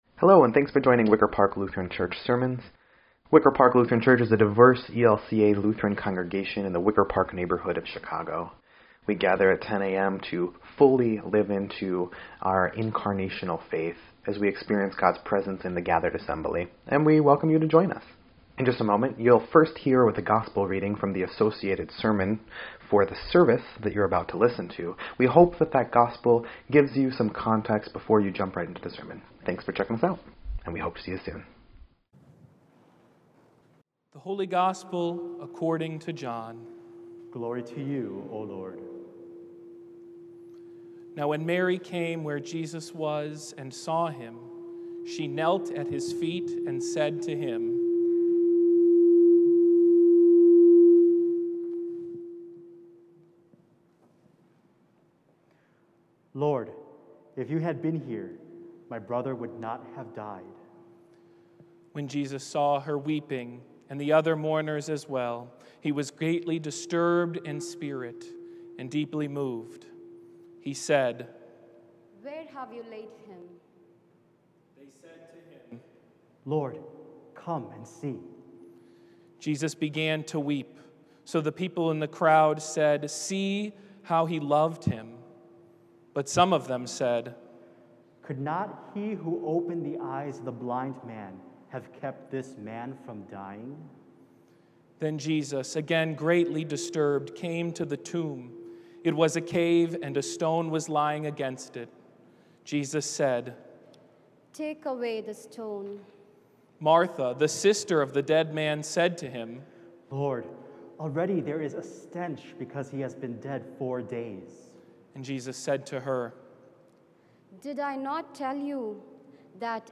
11.7.21-Sermon_EDIT.mp3